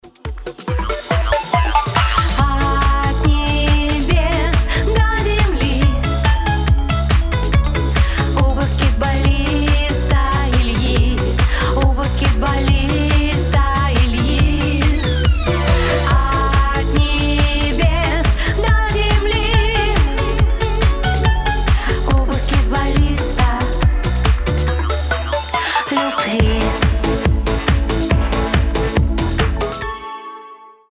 • Качество: 320, Stereo
легкие